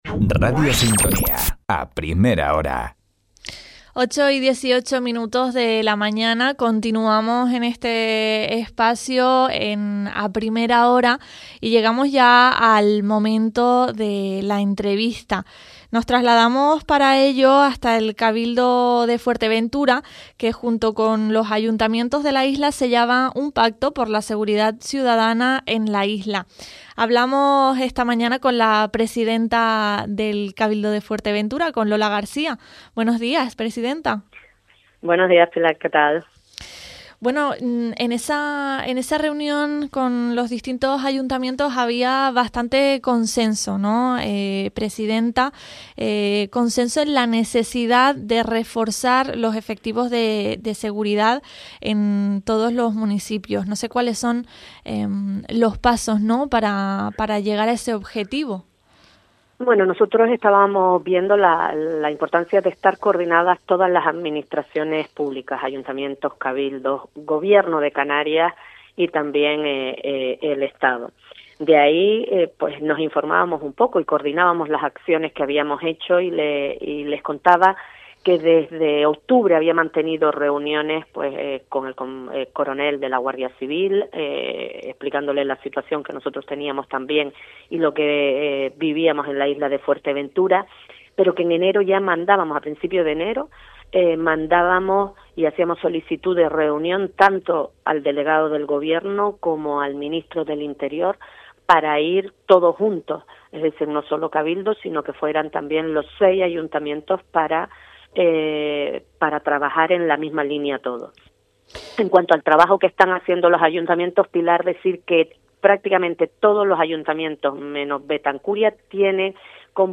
Conversado con la presidenta del Cabildo de Fuerteventura, Lola García, quien ha indicado que se seguirán manteniendo encuentros sobre seguridad
Entrevistas